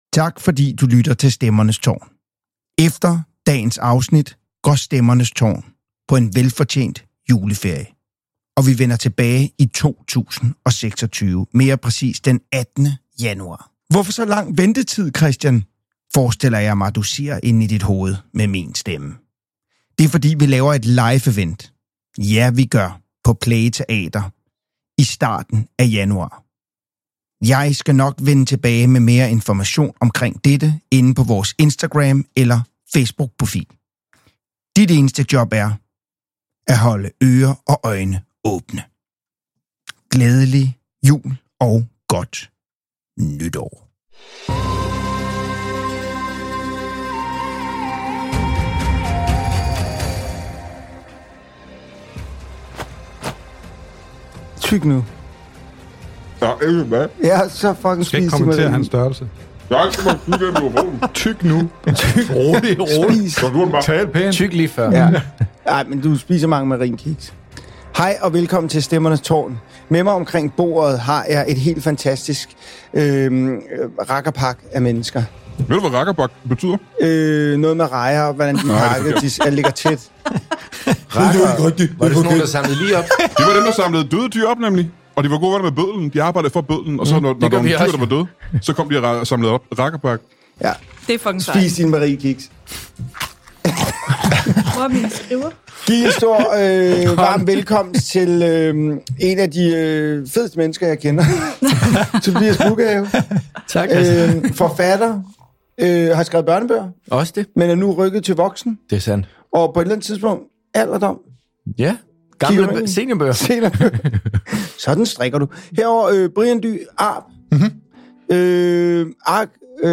Mange nye stemmer vil komme til, i en gode sammeblandning med folk I kender lyden af i forvejen.